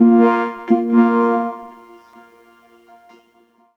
Track 10 - Vocoder 01.wav